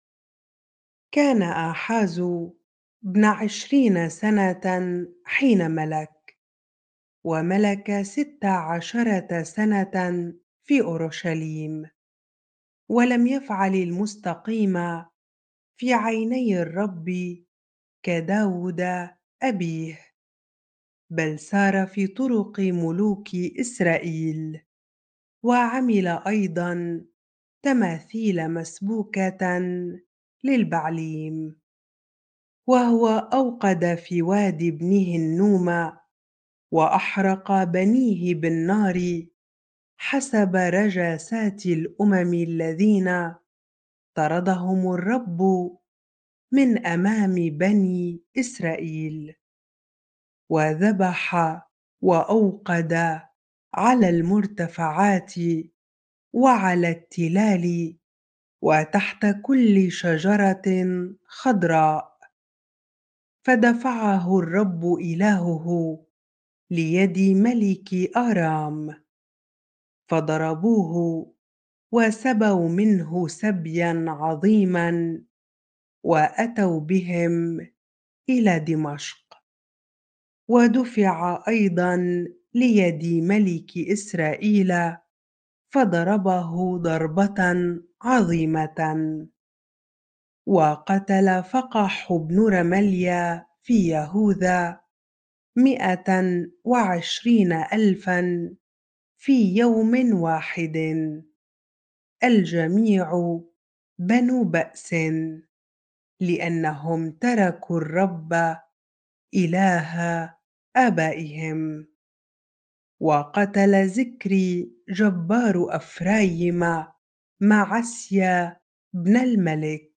bible-reading-2 Chronicles 28 ar